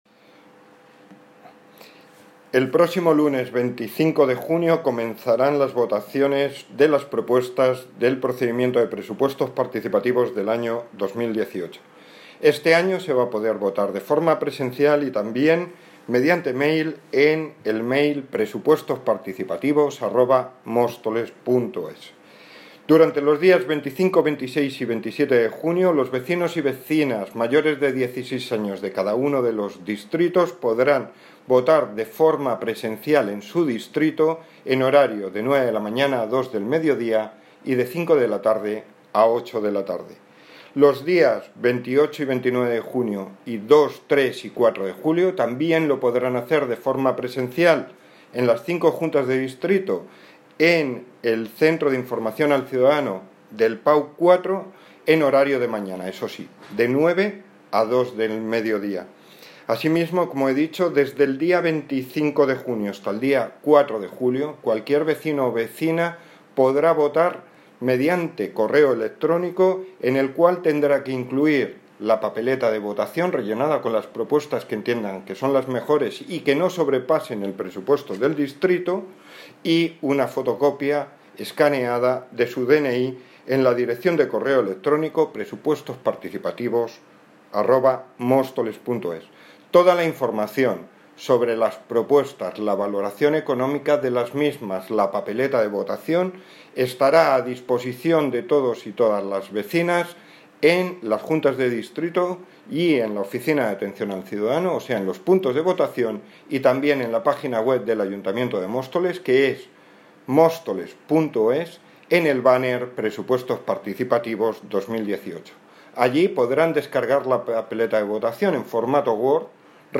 Audio - Javier Gómez (Concejal de Hacienda, Transporte y Movilidad) Sobre Presupuestos Participativos